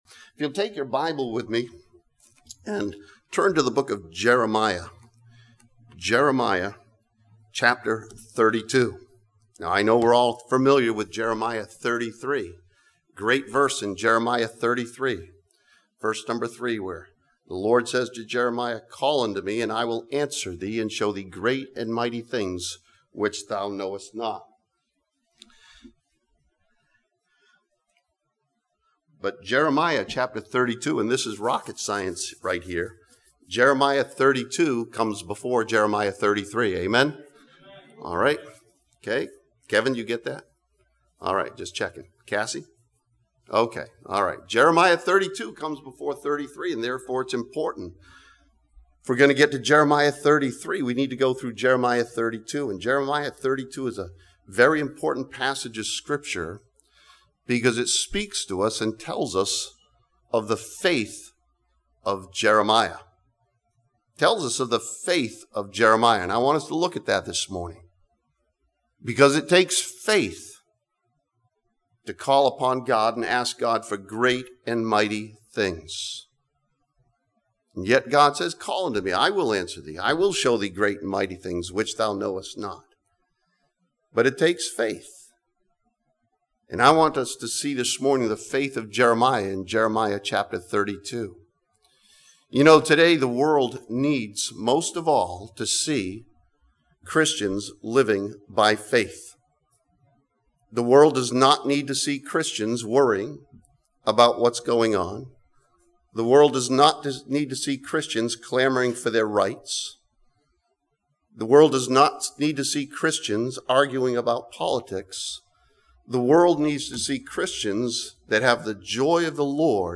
This sermon from Jeremiah chapter 32 challenges believers to have a personal faith in Jesus Christ that will affect your life.